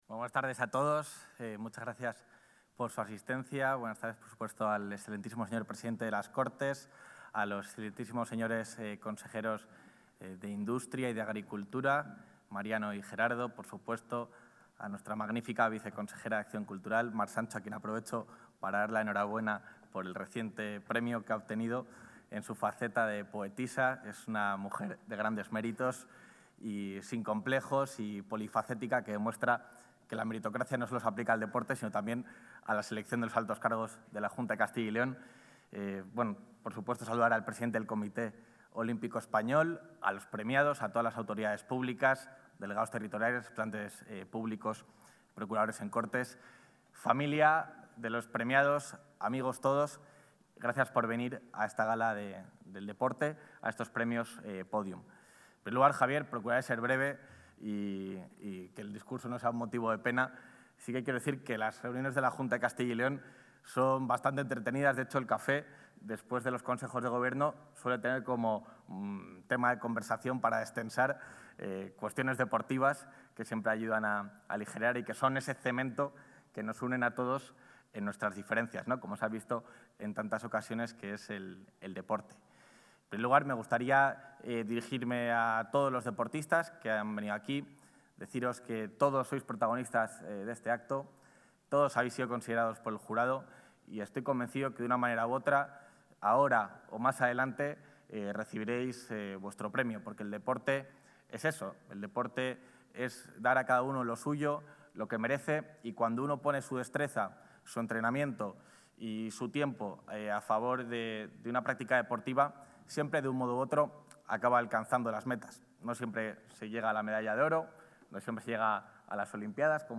Intervención del vicepresidente de la Junta.
El vicepresidente de la Junta de Castilla y León, Juan García-Gallardo, ha presidido el acto de entrega de los Premios Pódium del Deporte de Castilla y León, que se ha celebrado esta tarde en el Centro Cultural Miguel Delibes, en Valladolid.